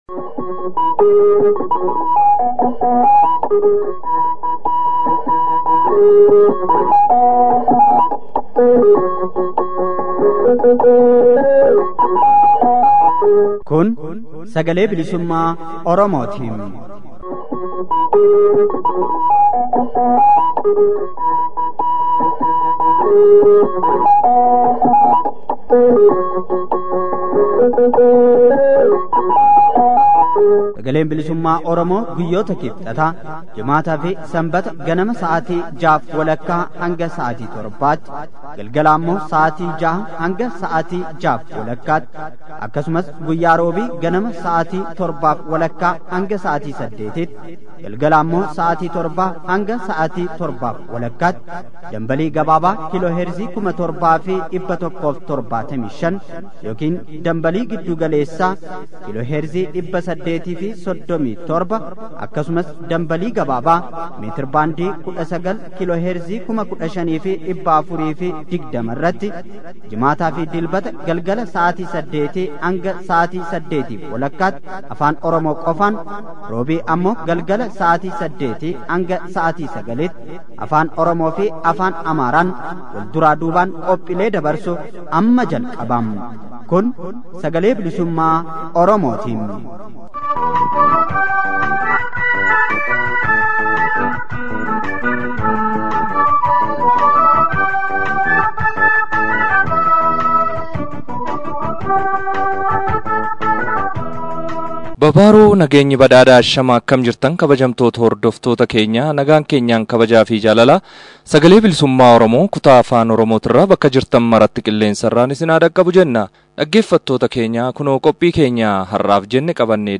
SBO: Waxabajjii 09 bara 2017. Oduu, Waamicha Qabsoo ABO irraa dhiyaate fi Dhimma Jijjiiramuu qubee Afaan Oromoo irratti Gaaffii fi deebii